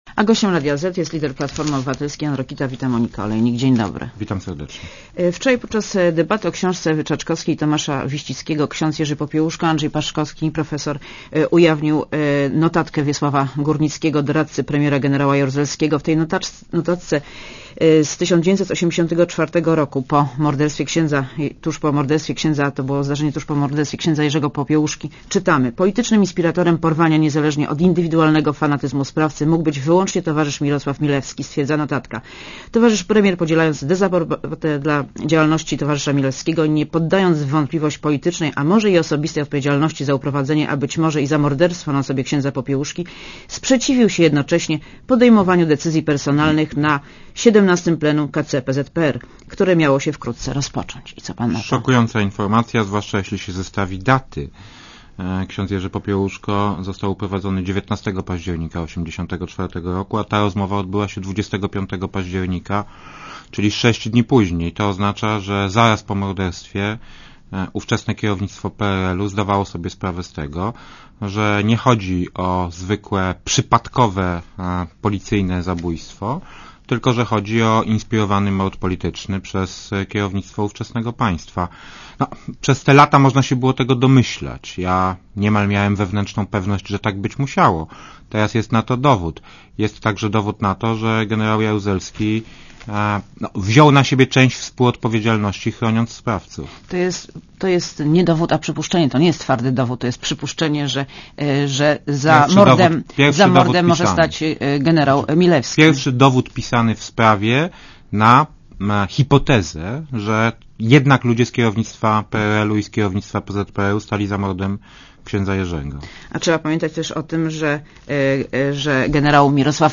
Jan Rokita w Radiu Zet (PAP)